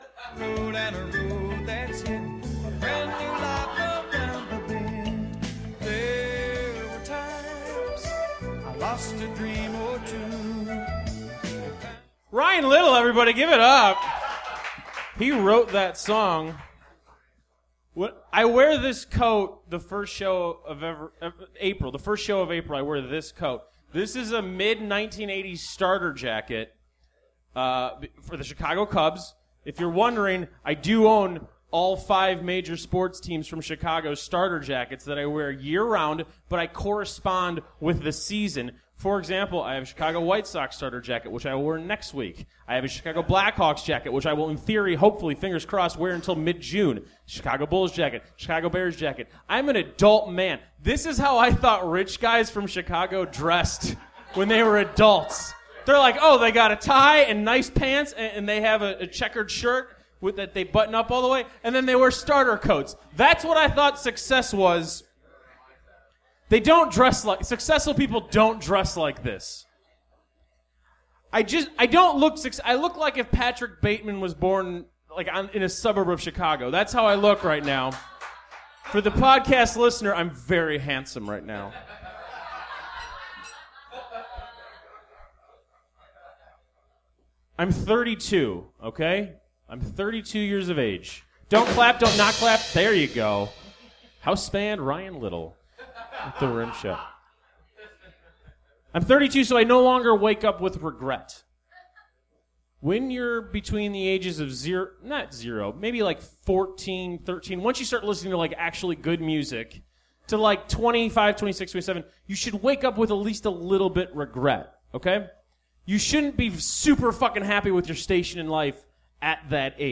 for house band/sound effects
The Wonderland Ballroom for venue Washington D.C. for city